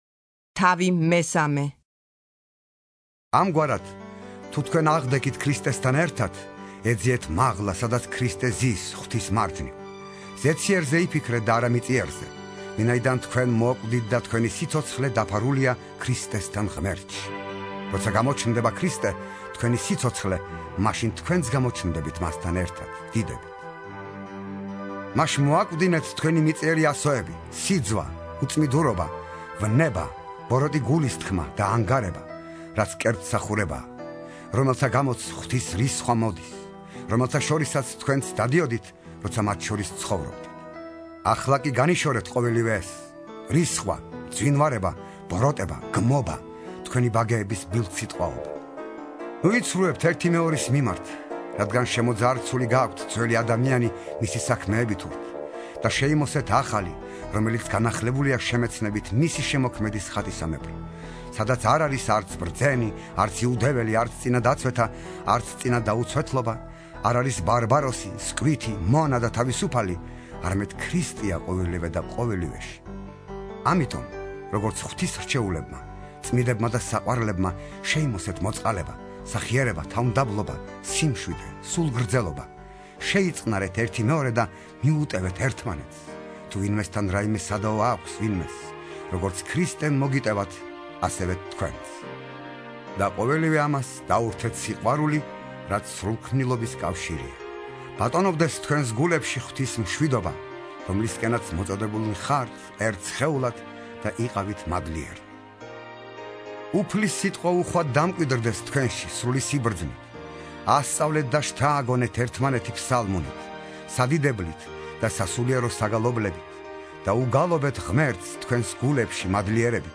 (51) ინსცენირებული ახალი აღთქმა - პავლეს ეპისტოლენი - კოლასელთა მიმართ